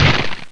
BikeCrash_00.mp3